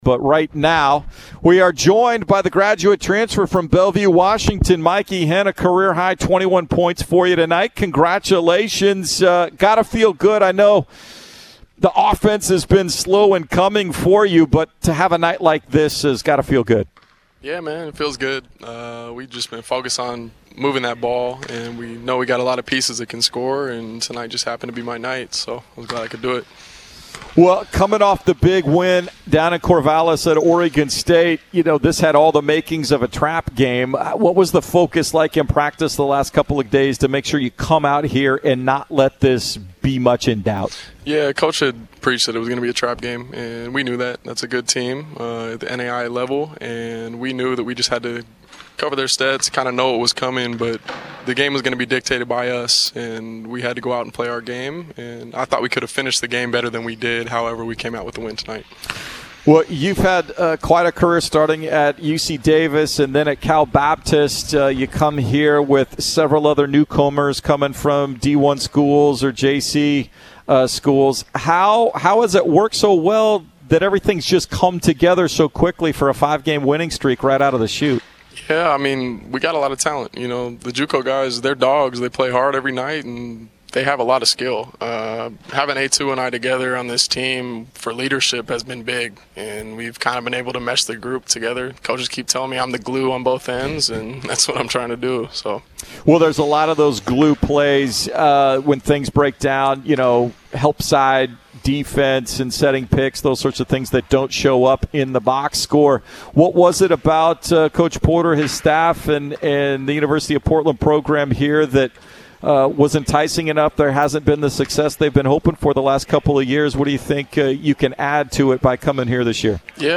Post-Game Interview vs. College of Idaho
Men's Basketball Radio Interviews